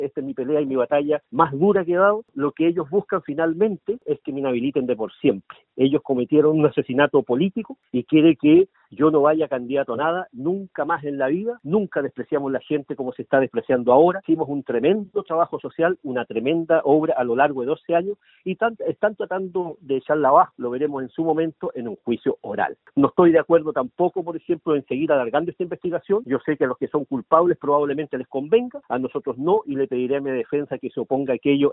En conversación con Radio Bío Bío, el ex alcalde defendió su inocencia y negó rotundamente llegar a un juicio abreviado porque dijo que “eso es para los culpables”.